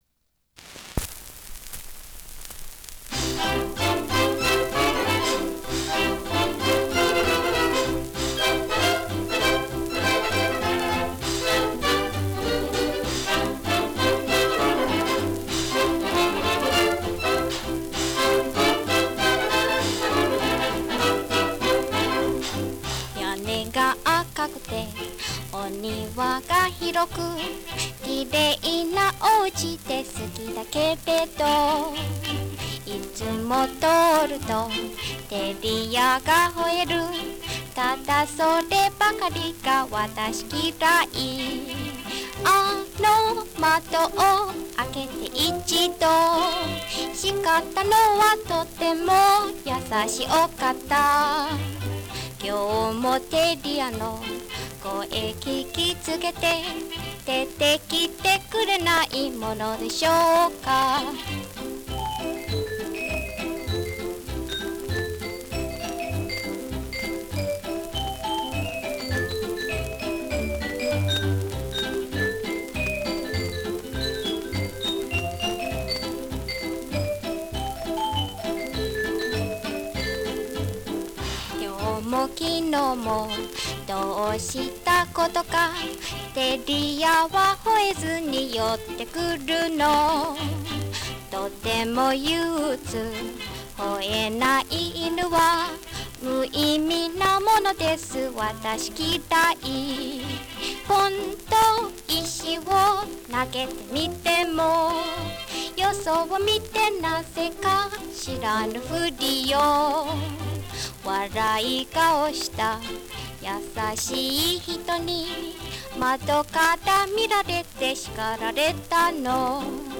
(one speaker monaural